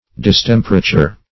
Distemperature \Dis*tem"per*a*ture\ (?; 135), n.